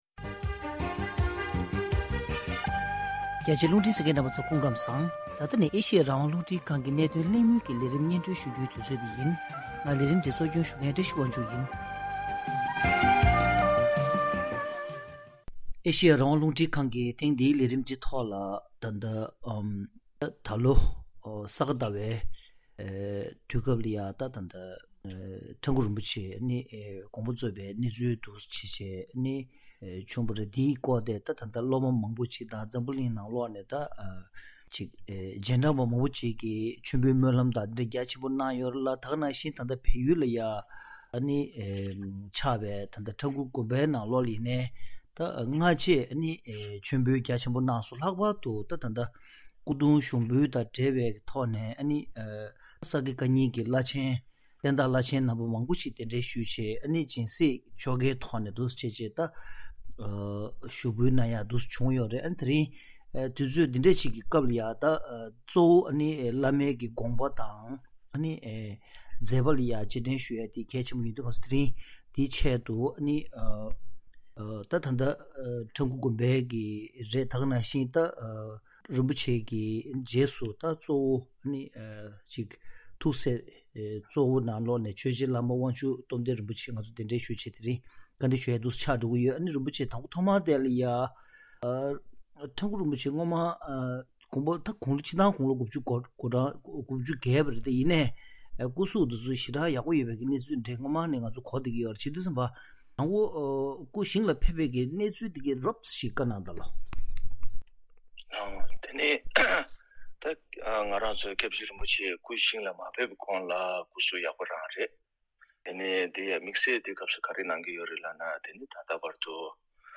དེ་རིང་གནད་དོན་གླེང་མོལ་གྱི་ལས་རིམ་ནང་།